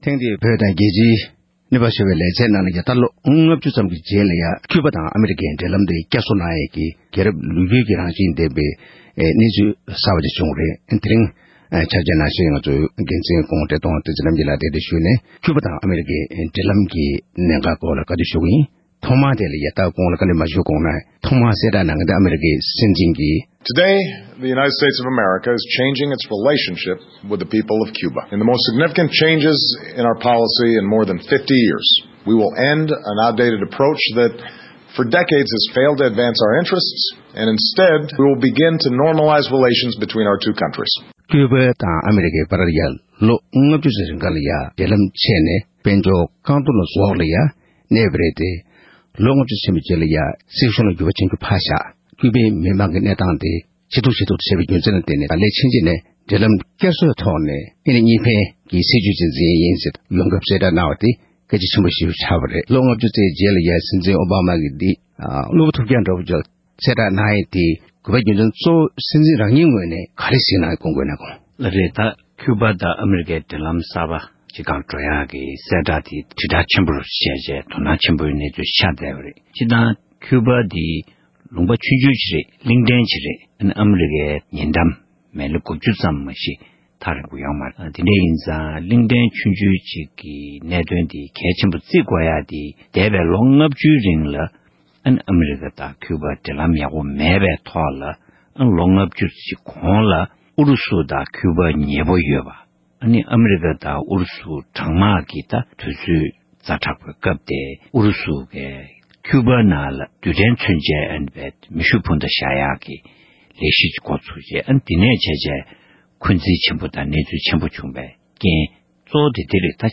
༄༅། །བོད་དང་རྒྱལ་སྤྱིའི་གནས་བབས་ཞེས་པའི་ལེ་ཚན་ནང་། ལོ་ངོ་༥༠ཙམ་གྱི་རྗེས་ཨ་རི་དང་ཀྱུཡུ་བྷ་གཉིས་ཀྱི་དབར་འབྲེལ་ལམ་བསྐྱར་མཐུད་བྱུང་བའི་སྐོར་བཀའ་ཟུར་བཀྲས་མཐོང་བསྟན་འཛིན་རྣམ་རྒྱལ་ལགས་དང་ལྷན་དུ་གླེང་མོལ་ཞུས་པ་ཞིག་གསན་རོགས་གནང་།།